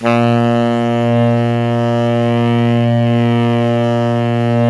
SAX_sma#2x    12.wav